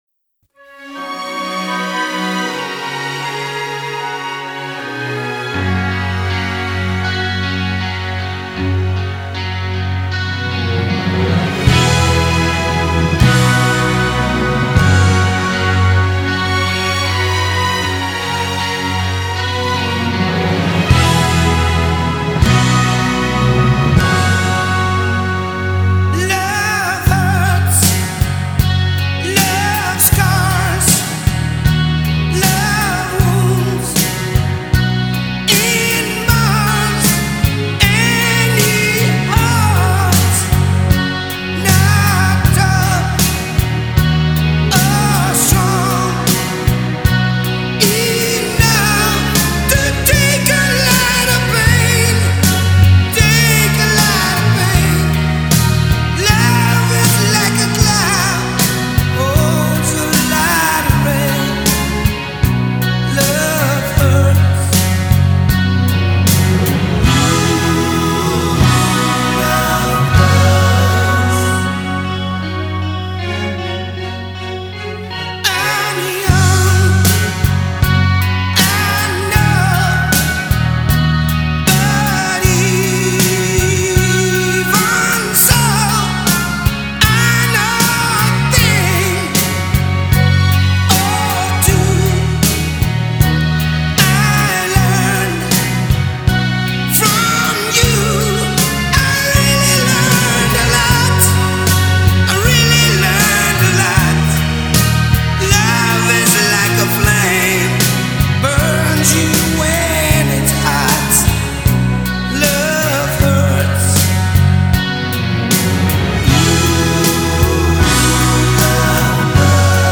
Rock Orchestra Version